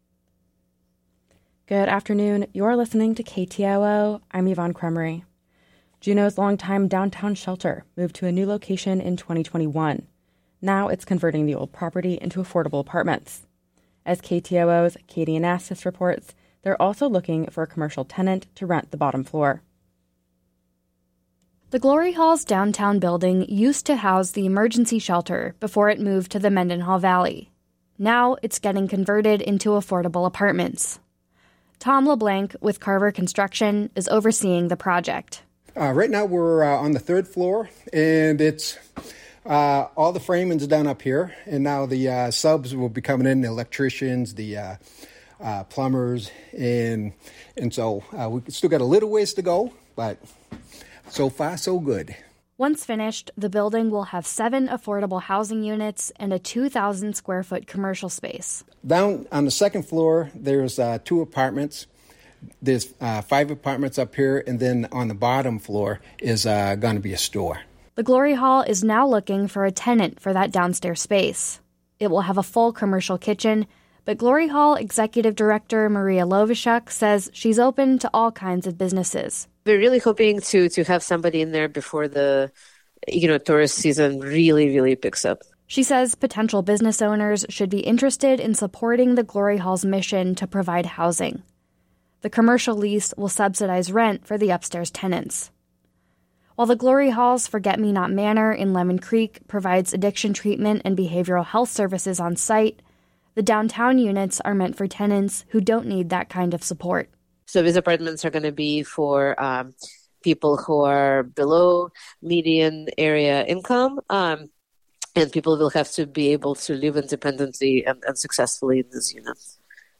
Newscast – Thursday. Jan. 4 2024